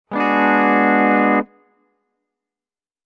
Descarga de Sonidos mp3 Gratis: guitarra a 4.